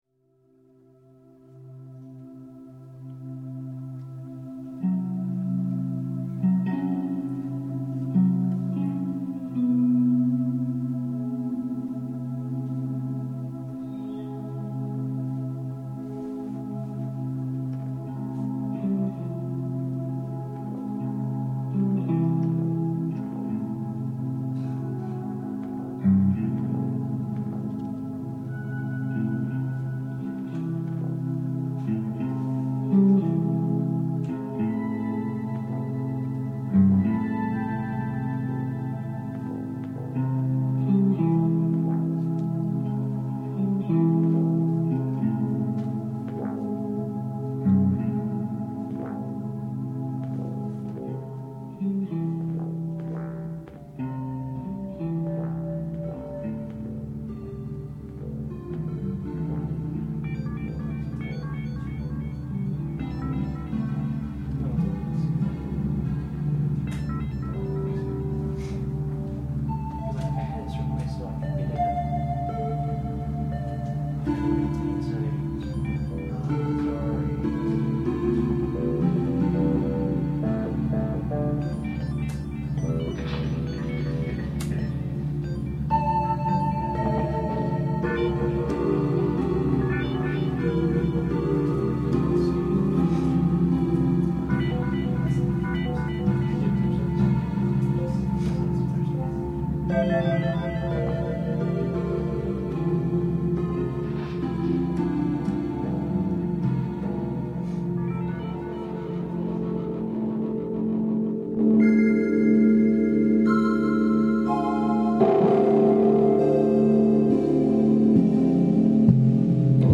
wandering noisefront glasnost from the manmachine.
hours of crackling melodies and charred air.